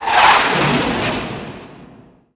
RocketV1-2.mp3